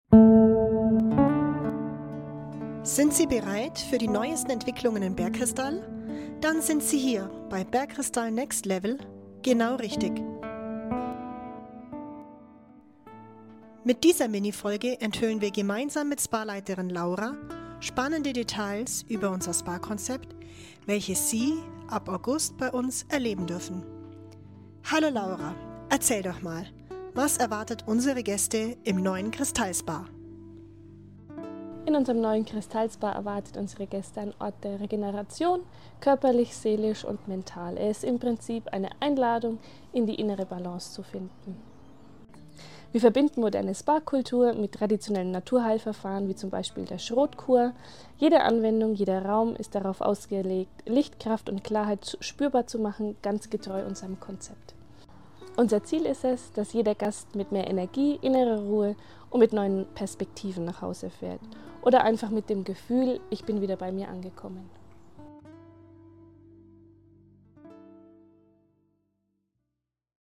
Interview: Was erwartet unsere Gäste im neuen Kristall SPA?